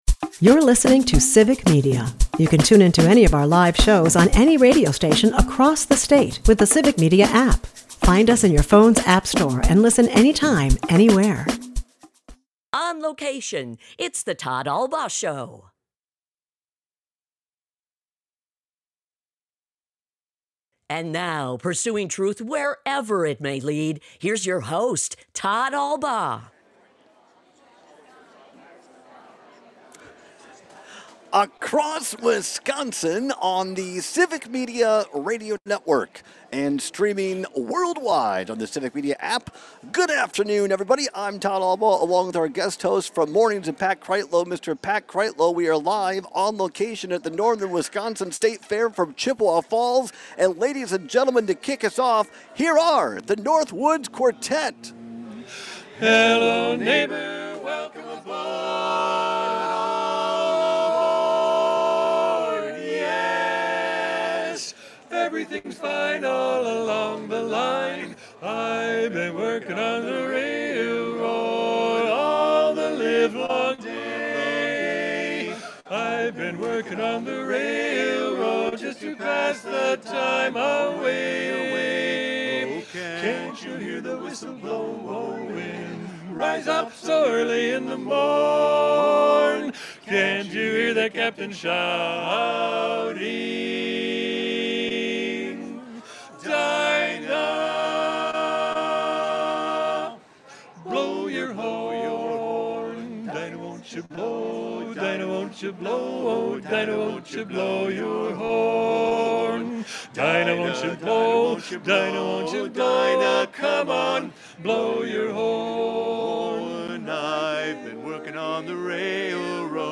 We herald in the beginning of the show with an excellent performance from the Northwoods Quartet, a new barbershop quartet from the area.&nbsp